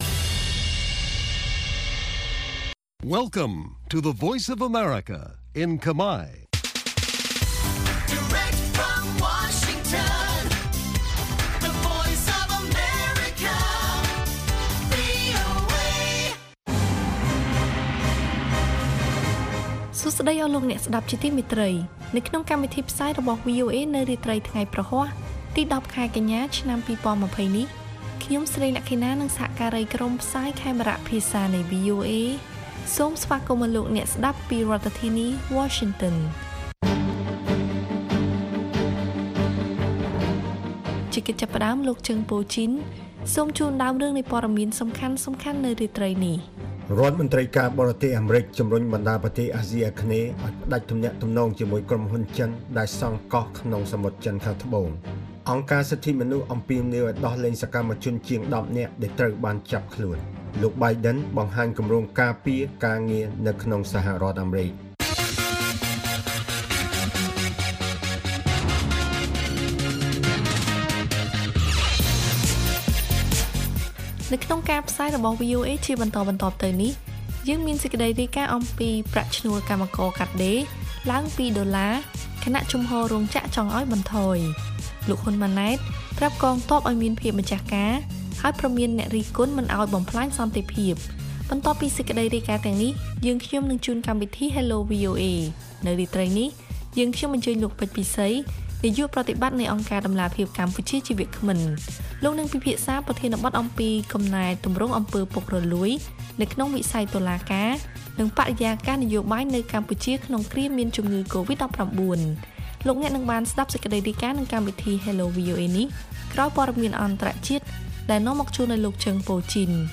ព័ត៌មានពេលរាត្រី